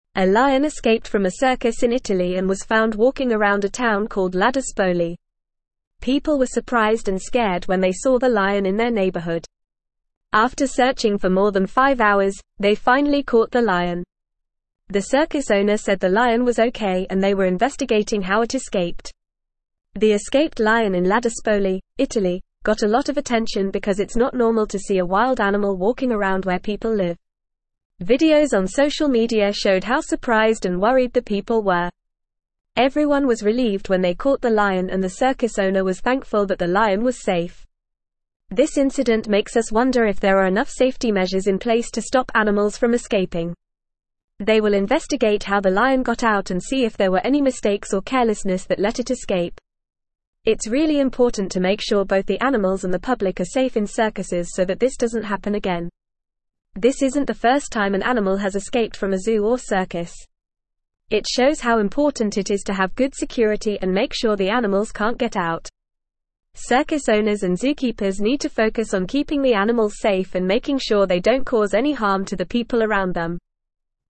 Fast
English-Newsroom-Upper-Intermediate-FAST-Reading-Circus-Lion-Escapes-in-Italy-Causes-Town-Panic.mp3